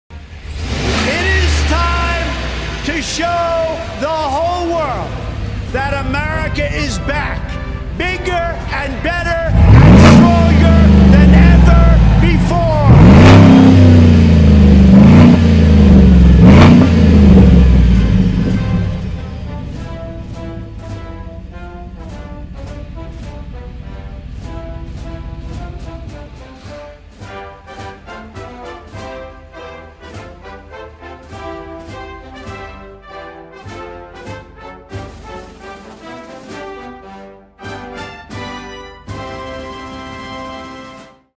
Get yourself a set of sound effects free download By quicktimeperformance 0 Downloads 7 months ago 41 seconds quicktimeperformance Sound Effects About Get yourself a set of Mp3 Sound Effect Get yourself a set of cutouts so you can MAKE AMERICA LOUD AGAIN! With a set of Quick Time Electric Cutouts, you can take you ride from mild to wild at the push of a button.